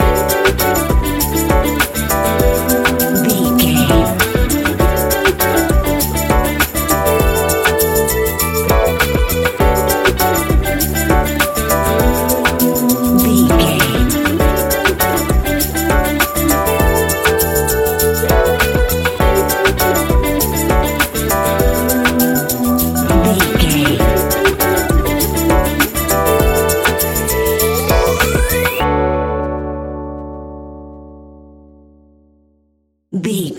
Ionian/Major
D♭
laid back
Lounge
sparse
new age
chilled electronica
ambient